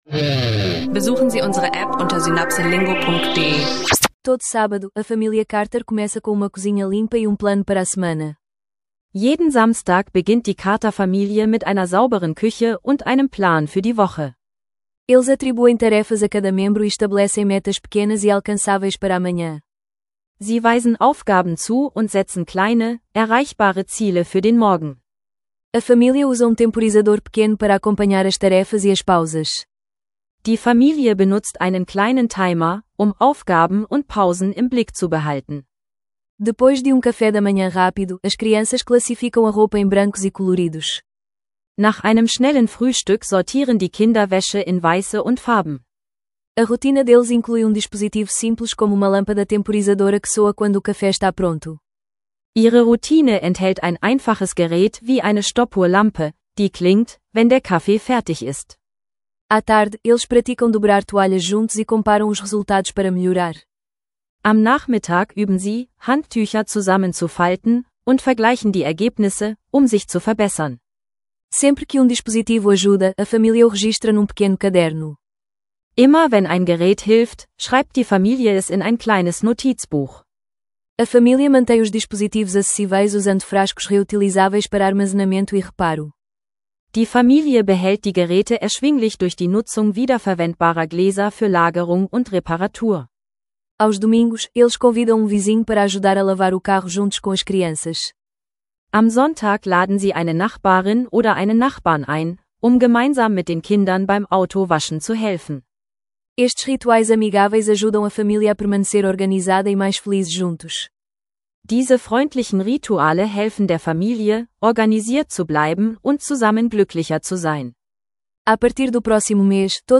Eine alltagstaugliche Lektion über Haushaltshelfer, Timer und einfache Geräte – Portugiesisch lernen leicht gemacht mit SynapseLingo.